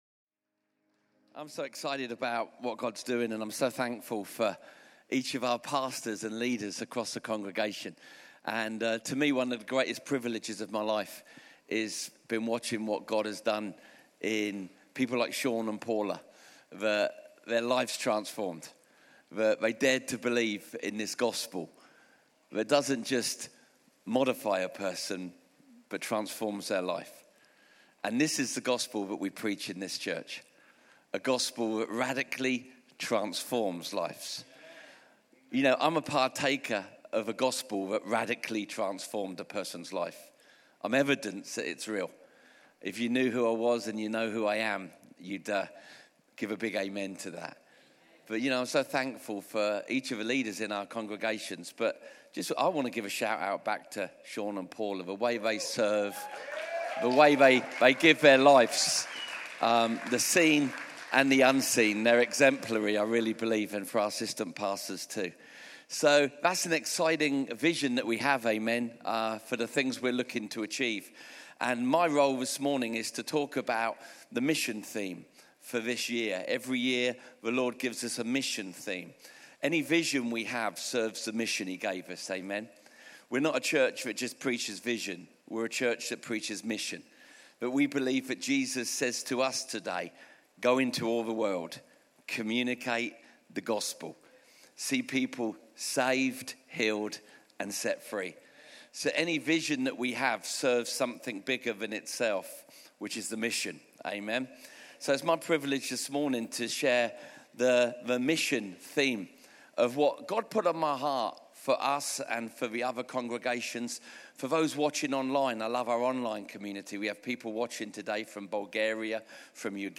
Vision Sunday Message